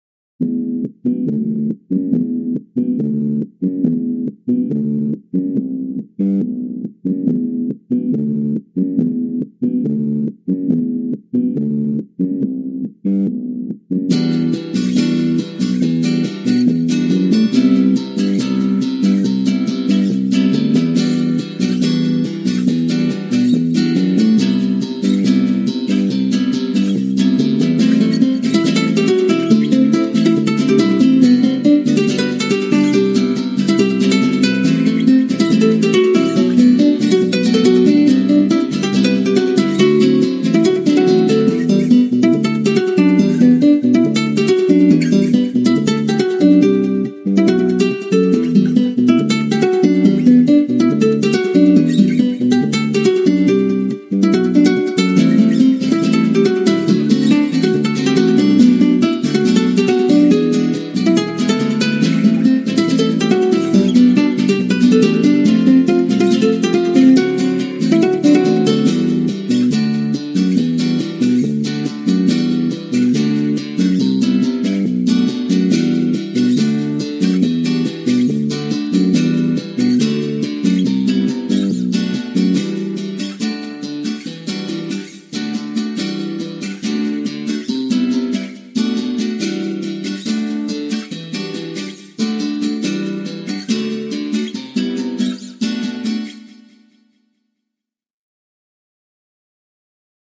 latin guitar drum bass